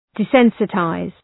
Προφορά
{dı’sensı,taız}